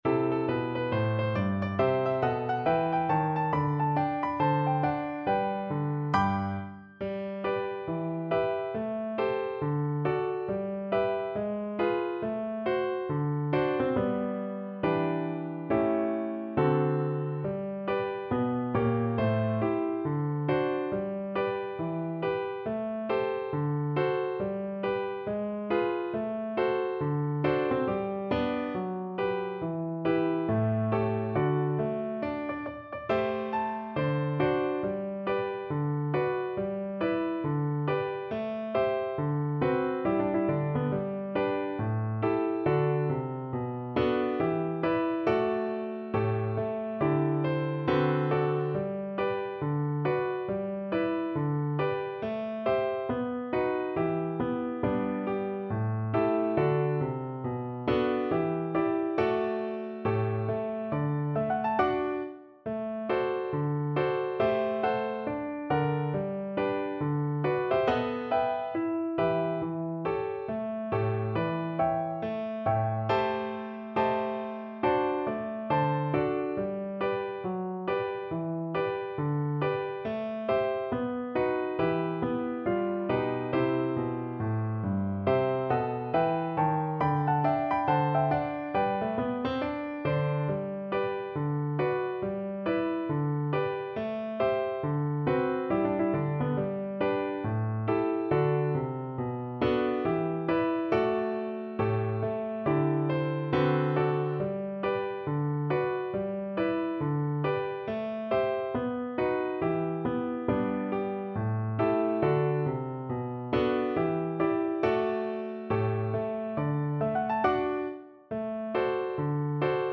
4/4 (View more 4/4 Music)
With a swing =c.69
Pop (View more Pop Viola Music)